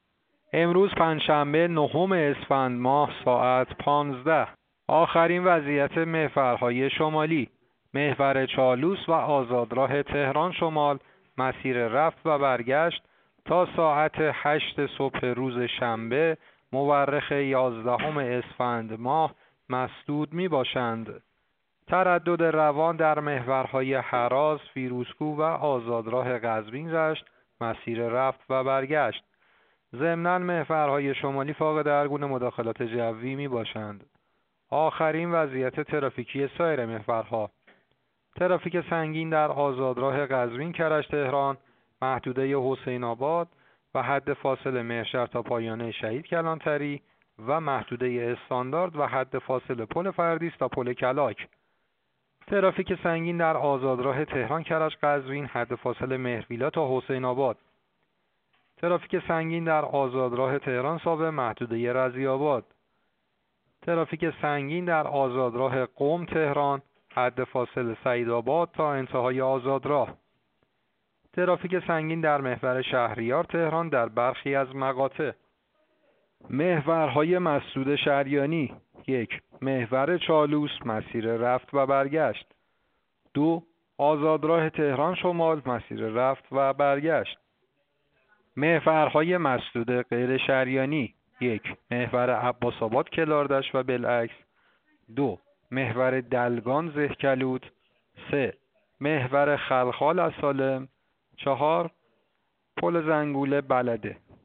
گزارش رادیو اینترنتی از آخرین وضعیت ترافیکی جاده‌ها ساعت ۱۵ نهم اسفند؛